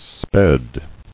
[sped]